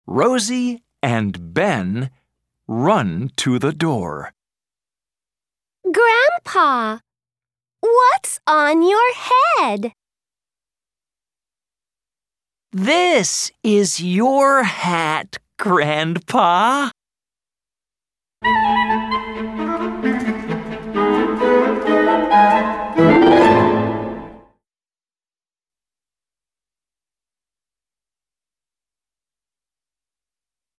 Track 5 Where's My Hat US English.mp3